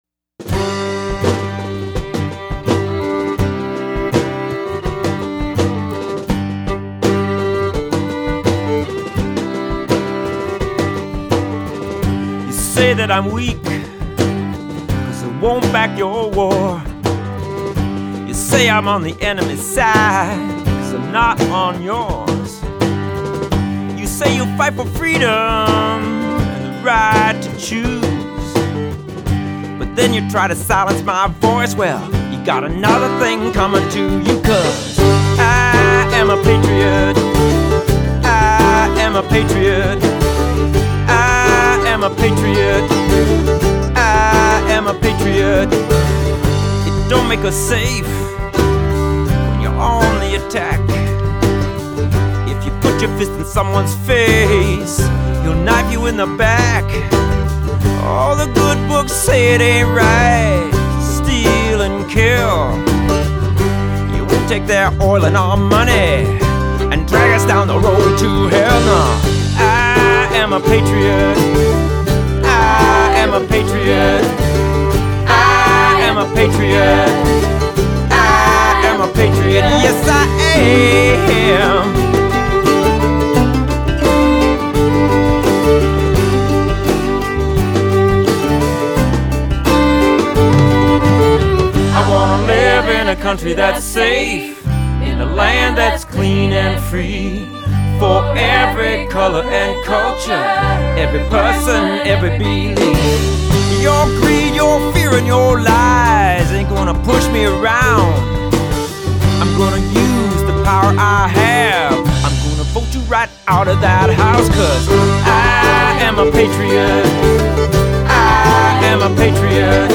A rousing anti-Bush single.